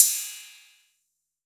808CY_8_Orig.wav